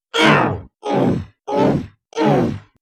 Cyborg hurt sound.
cyborg_hurt_0.mp3